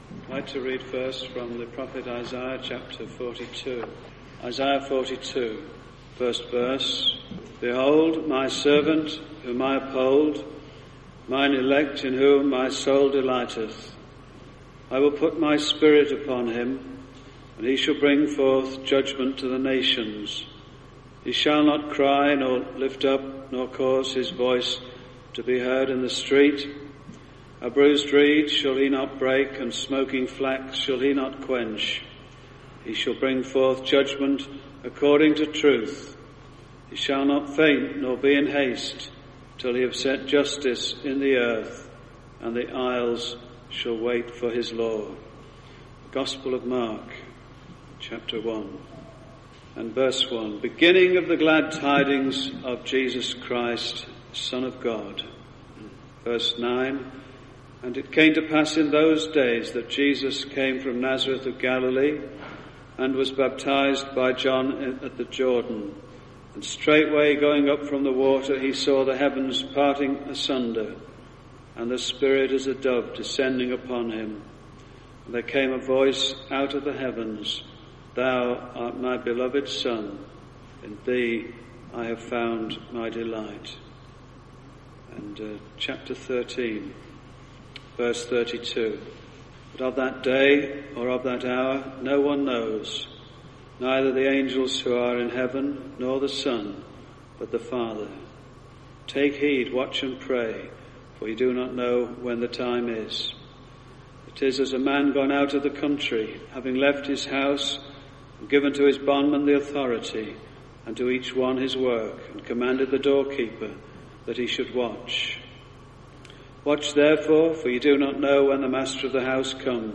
Bible Teaching (Addresses)